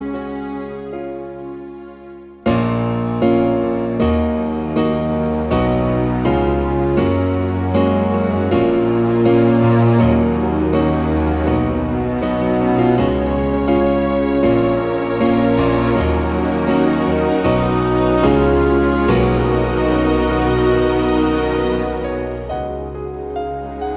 Duet Rock 4:14 Buy £1.50